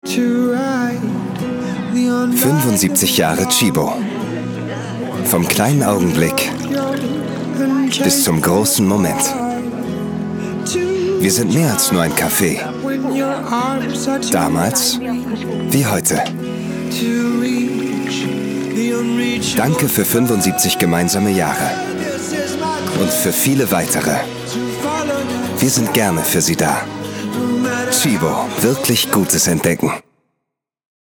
dunkel, sonor, souverän, sehr variabel, markant
Mittel minus (25-45)
Norddeutsch
Commercial (Werbung)